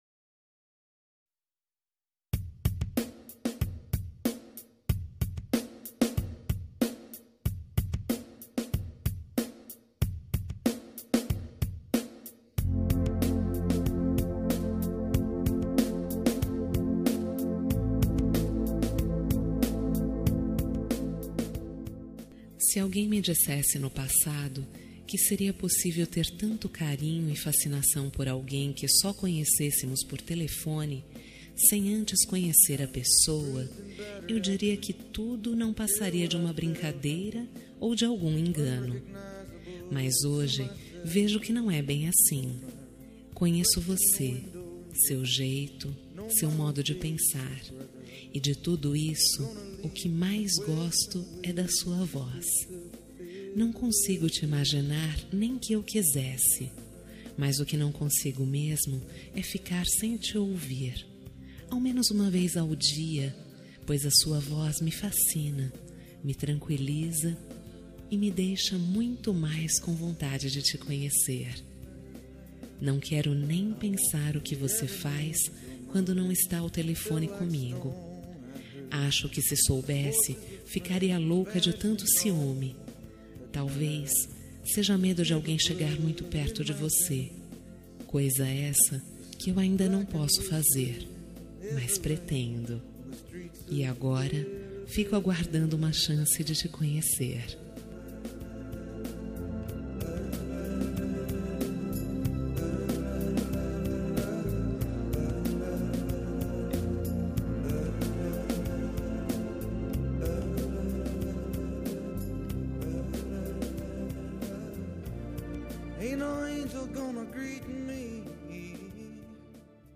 Telemensagem Romântica Virtual – Voz Feminina – Cód: 4091